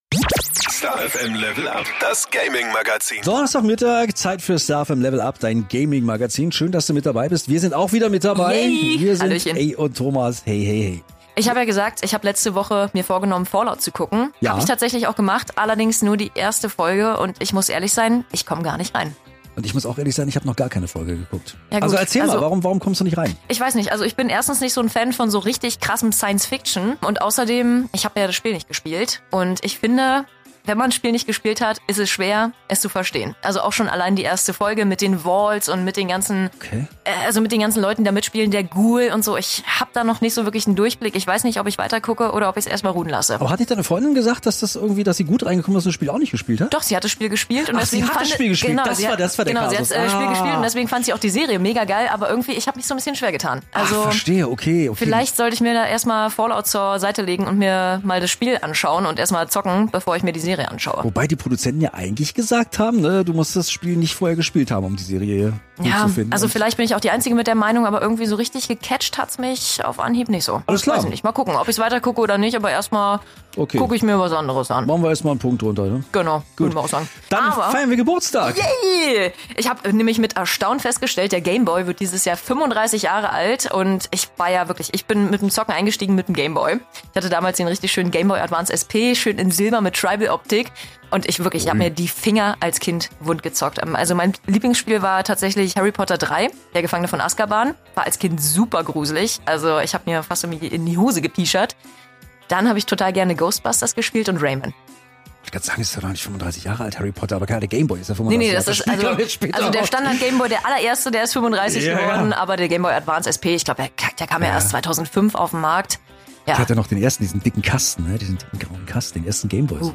LEVEL UP: Handheld-Retro-Konsolen, Assasasin's Creed, Mario Kart ~ STAR FM Level Up - Der Gaming Talk Podcast